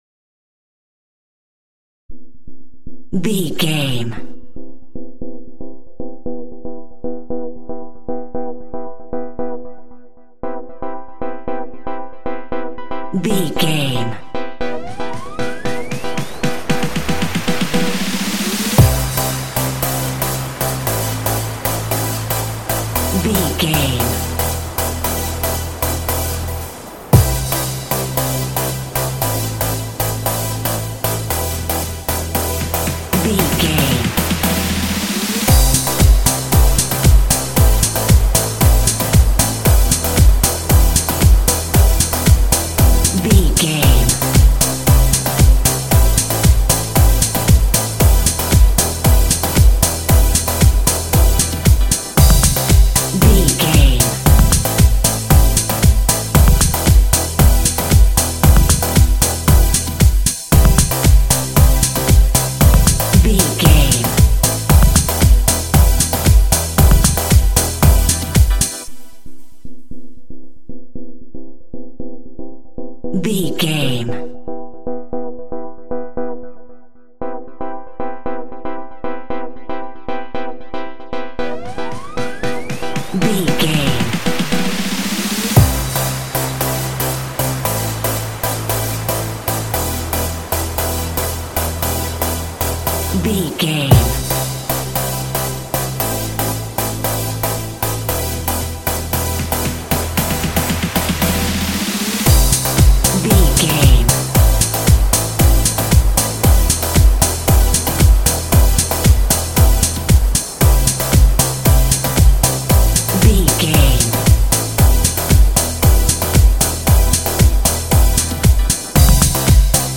Aeolian/Minor
dark
futuristic
groovy
aggressive
electric piano
synthesiser
drum machine
house
electro dance
techno
trance
synth leads
synth bass
upbeat